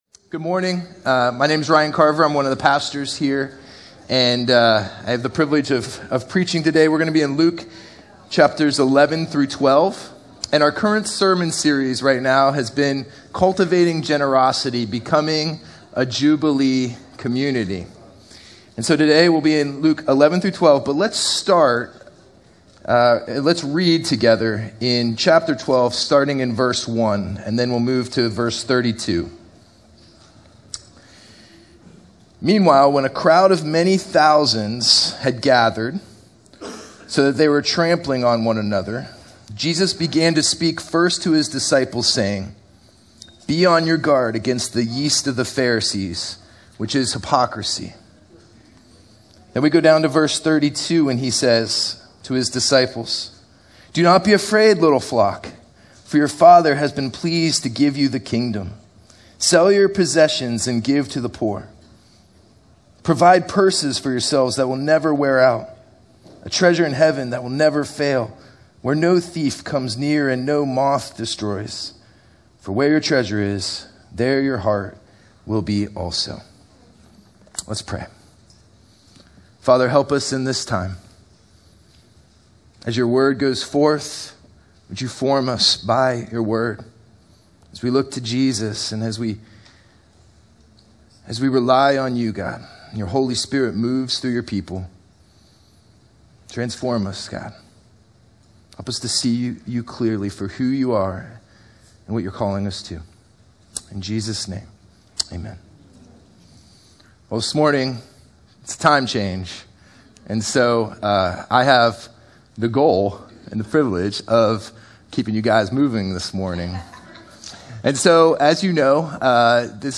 This Sunday we’ll explore Jesus’ warning and His vision for a Spirit-formed community. If something is always forming us, the real question is: what is quietly forming us right now?